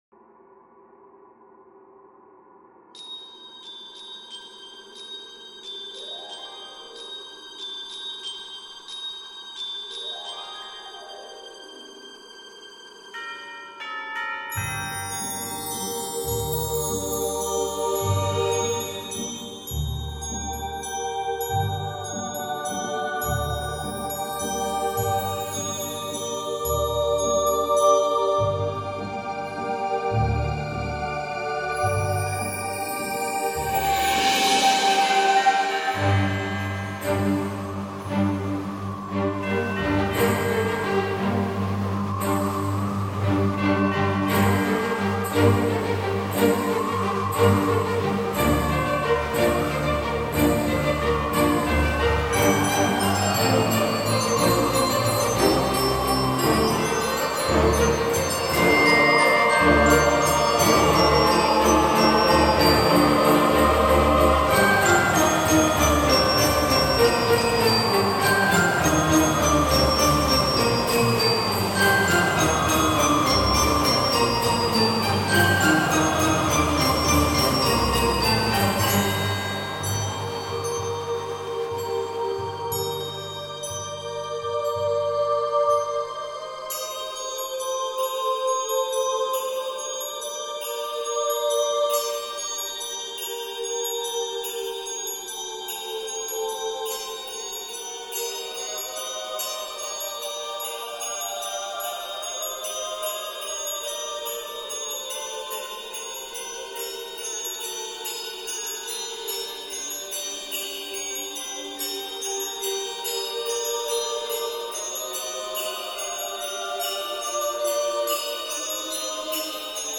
dark-christmas-music-carol-of-the-bells.mp3